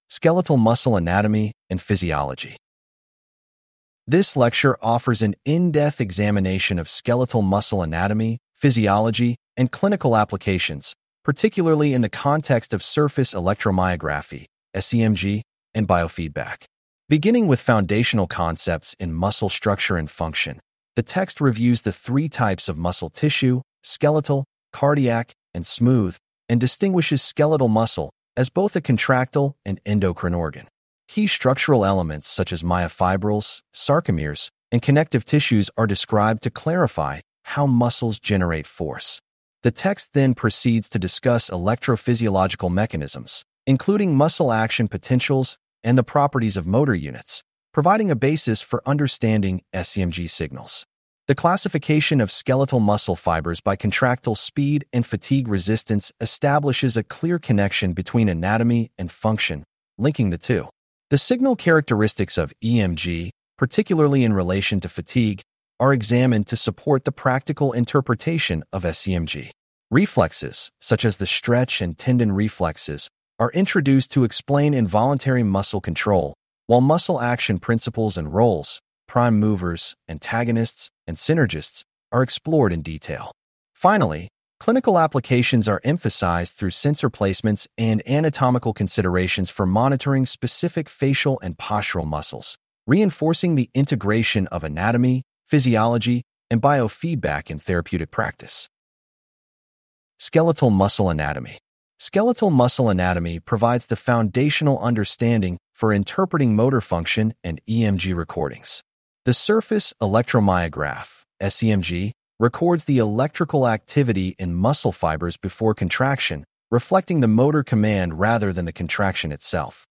This unit covers Three Types of Muscles, the Skeletal Muscle System, Motor Units, Muscle Action Potentials, Types of Skeletal Muscle Fibers, the EMG Signal, Skeletal Muscle Contraction, the Stretch Reflex, the Tendon Reflex, Muscle Action, Skeletal Muscles of Clinical Interest (Face), and Skeletal Muscles of Clinical Interest (Front and Back). Please click on the podcast icon below to hear a full-length lecture.